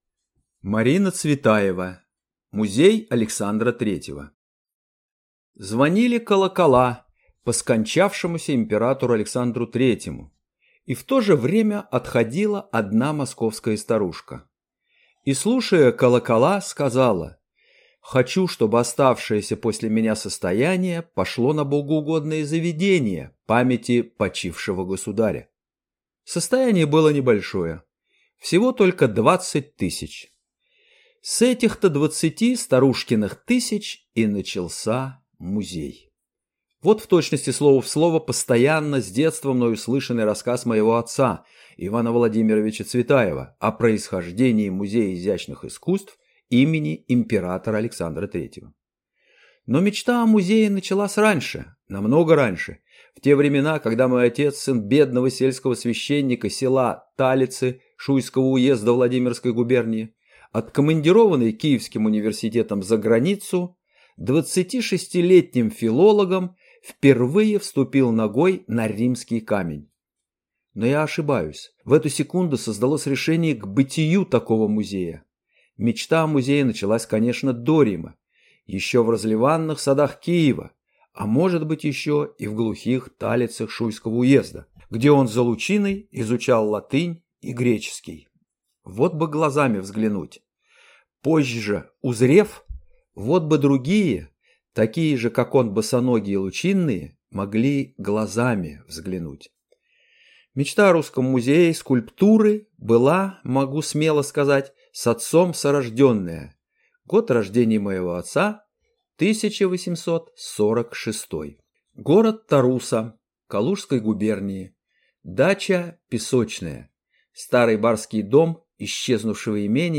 Аудиокнига Музей Александра III | Библиотека аудиокниг